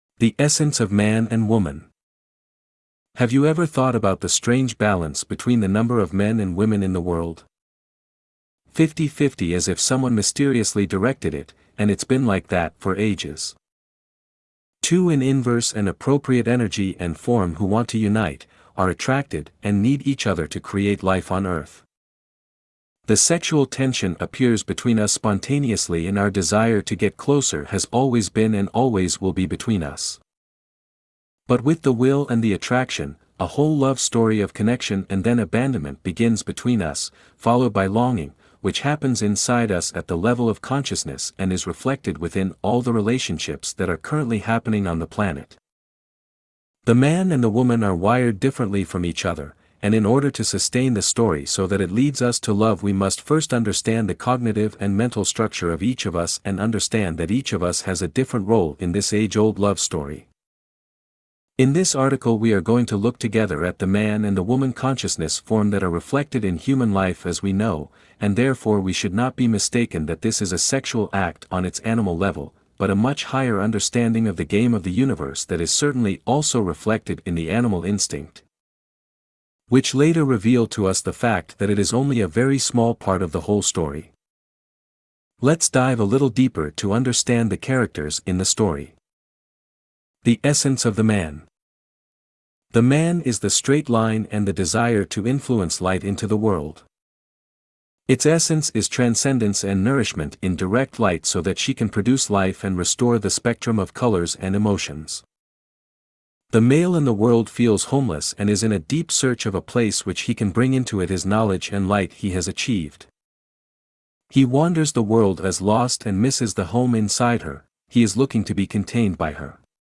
male voice